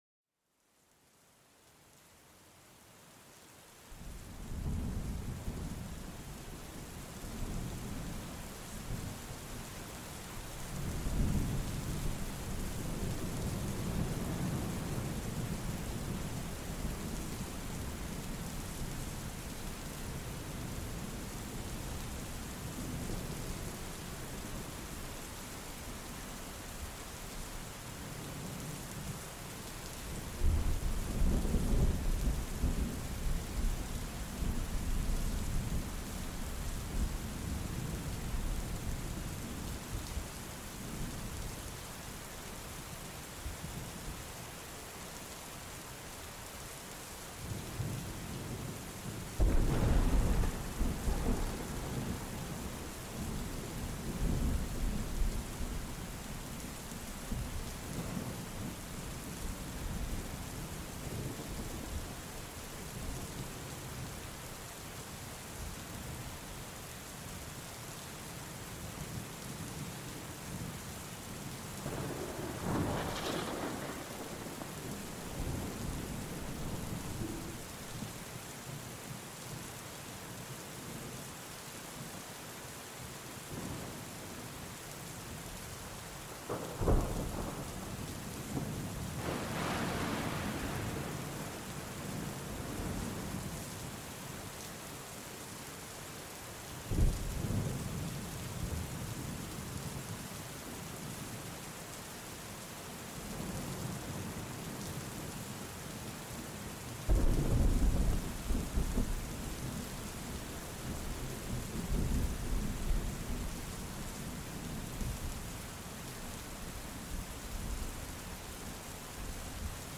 Descubre el poder bruto de una tormenta mezclada con la dulzura de la lluvia en este episodio único, un verdadero homenaje a las fuerzas de la naturaleza. Escucha el profundo rugido del trueno que resuena a través del cielo, seguido por el susurro calmante de la lluvia cayendo sobre la tierra nutricia.